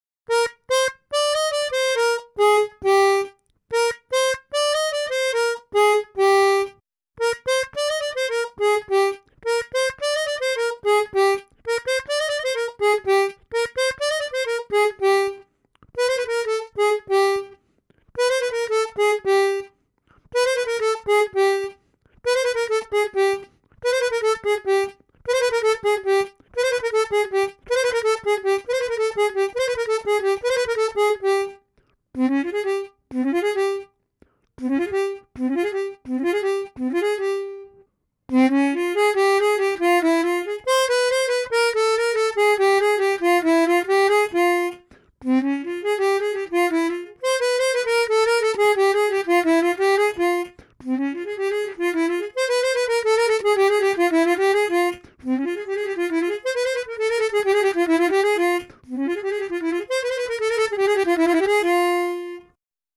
Проиграйте упражнения в максимально быстром темпе, используя разные способы взятия ноты «фа» и ноты «до» и вы поймете основной принцип замены нот.